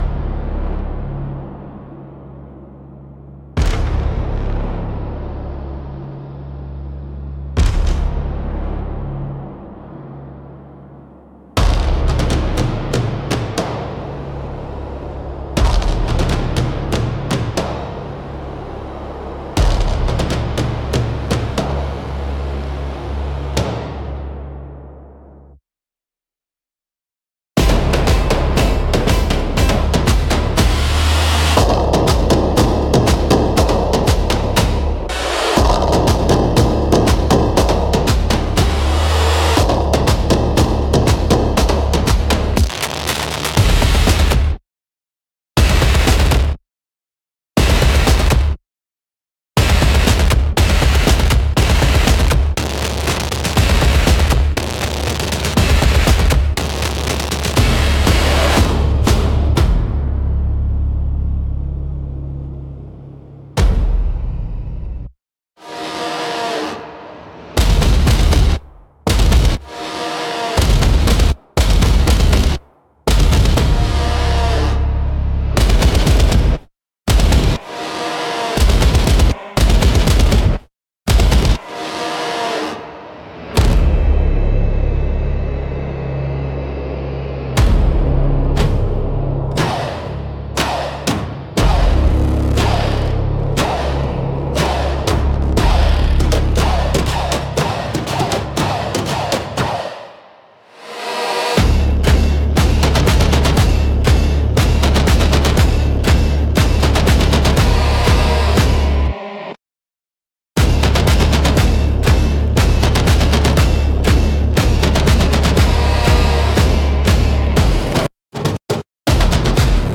Instrumental - Shamanic Frequency -2.29